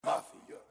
Vox